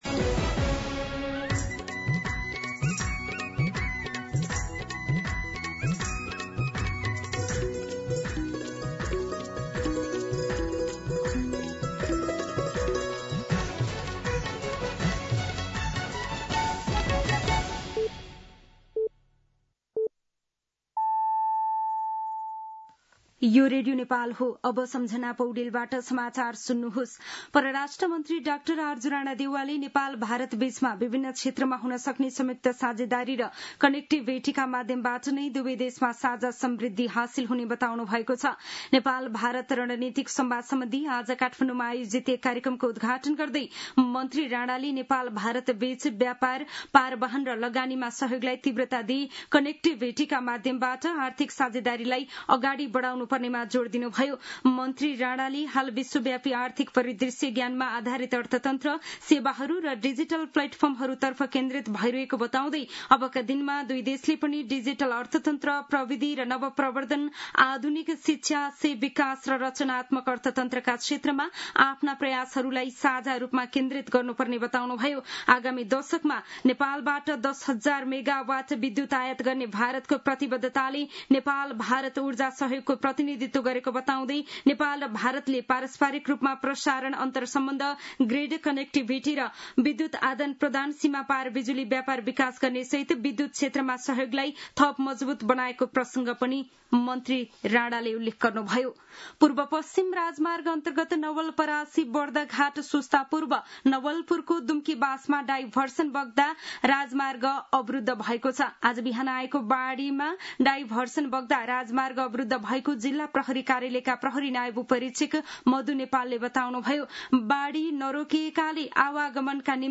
दिउँसो १ बजेको नेपाली समाचार : २९ जेठ , २०८२
1-pm-Nepali-News-1.mp3